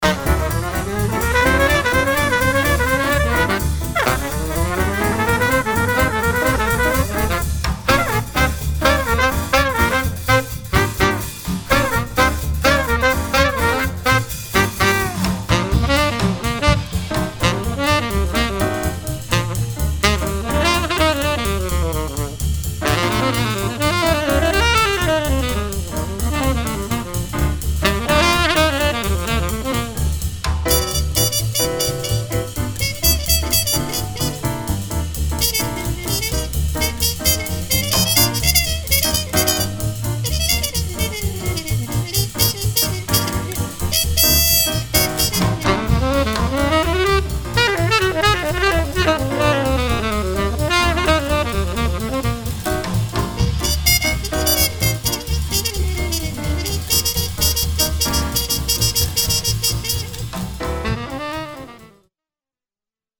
en concert
trompette
saxophone ténor
piano
contrebasse
batterie